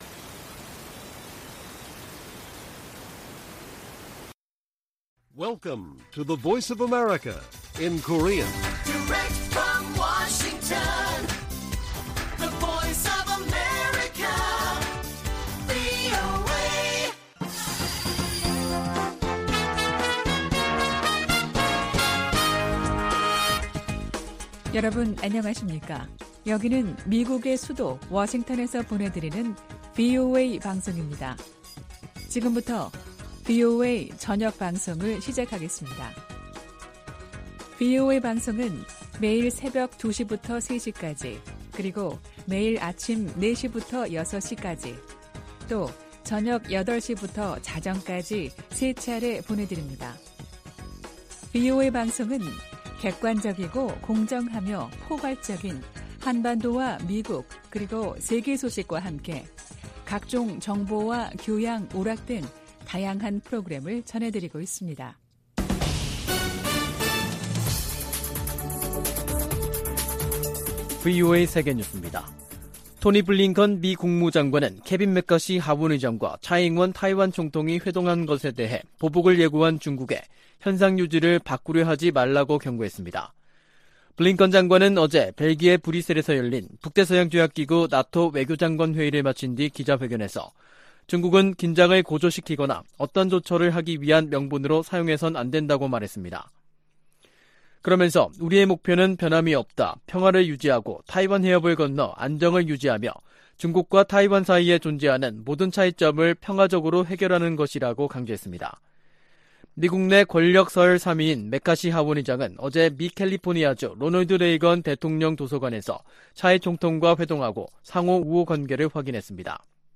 VOA 한국어 간판 뉴스 프로그램 '뉴스 투데이', 2023년 4월 6일 1부 방송입니다. 윤석열 한국 대통령은 최근 북한 간첩단 적발과 관련해 국민들이 현혹되지 않도록 대응 심리전이 필요하다고 강조했습니다. 북한이 아프리카 등지에 계속 군사 장비를 수출하고 있다고 유엔 안보리 대북제재위원회 전문가패널이 밝혔습니다. 한국을 방문한 미국 의원들이 윤석열 대통령을 면담하고 무역과 투자 강화 방안을 논의했습니다.